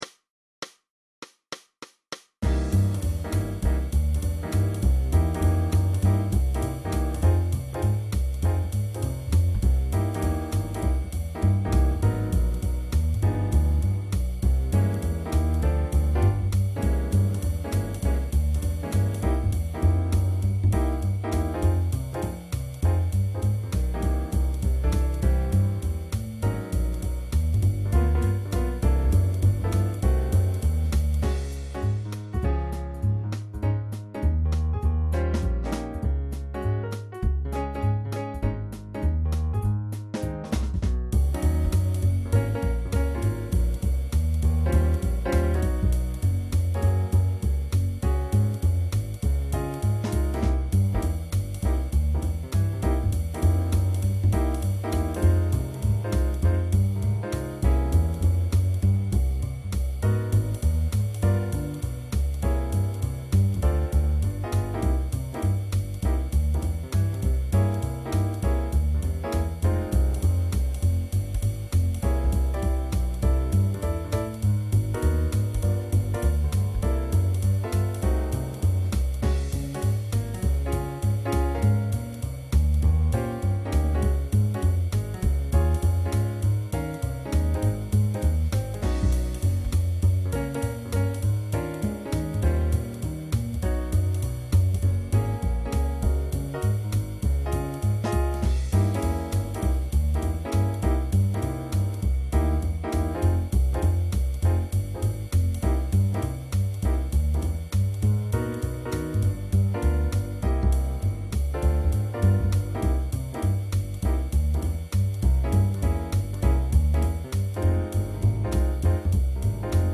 High School Jazz Band Practice Tracks 2007-2008
Each tune has a two bar intro (drums)